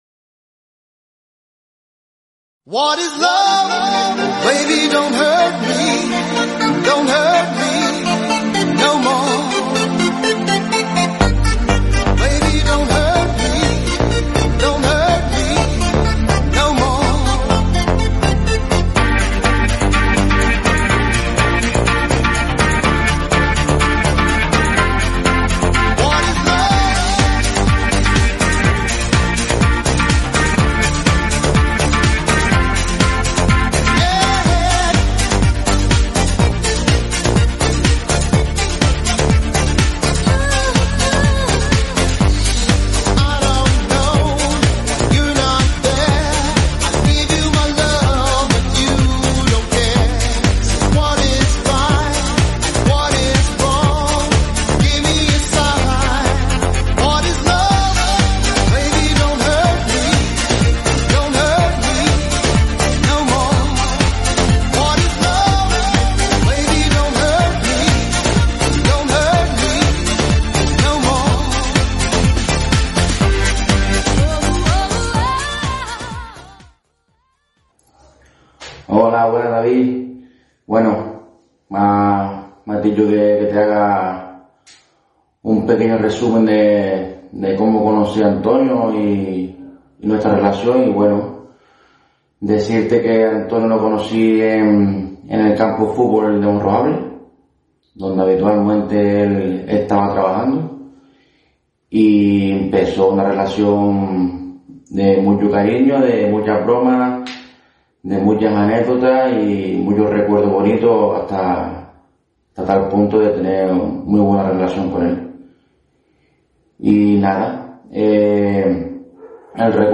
Entrevistas en SurFM, imagen de PodCast